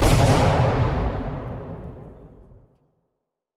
explosion_1.wav